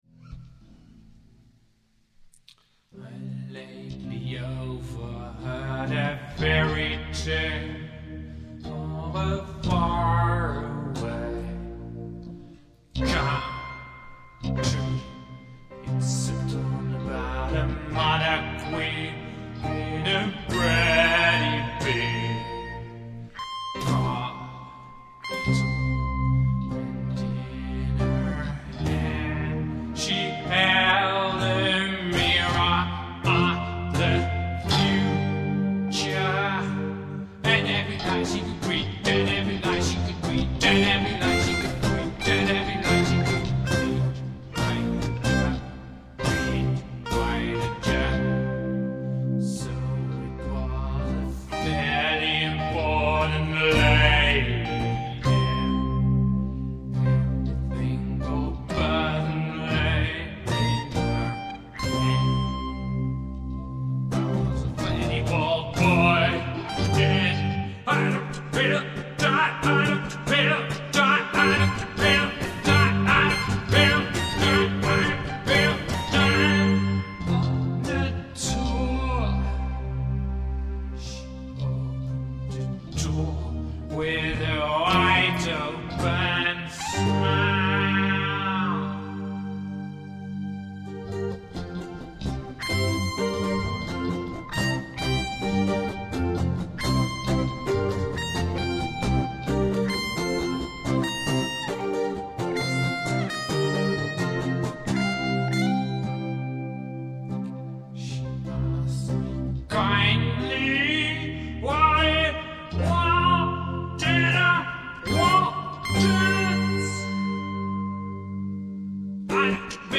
Rock/Blues MP3